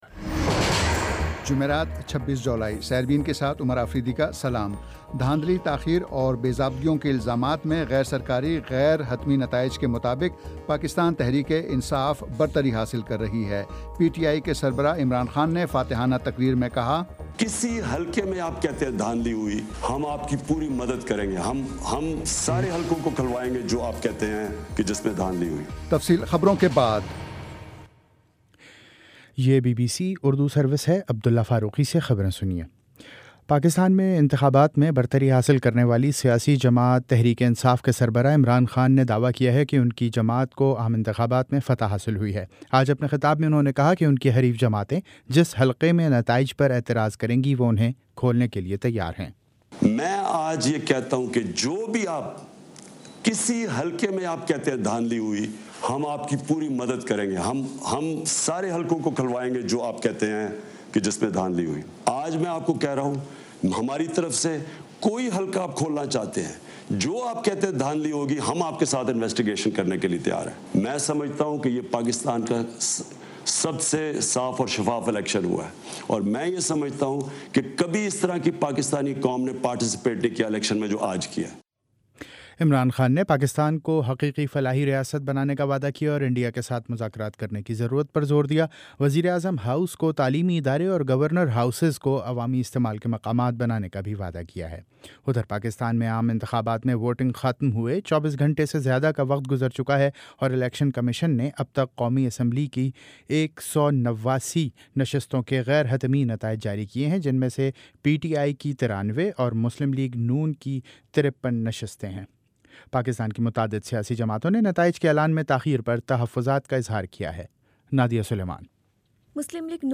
جمعرات 26 جولائی کا سیربین ریڈیو پروگرام